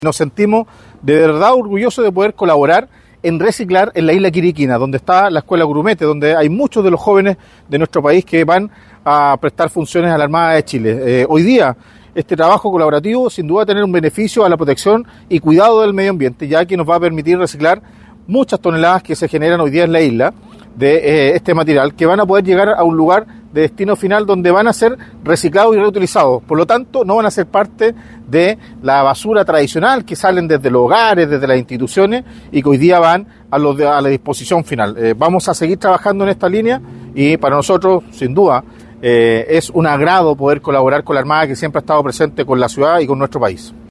El alcalde de Talcahuano, Eduardo Saavedra, destacó que este trabajo colaborativo “nos permitirá reciclar muchas toneladas de este material que se generan hoy en la isla, que llegarán a un lugar de destino final, donde serán reciclados y reutilizados”.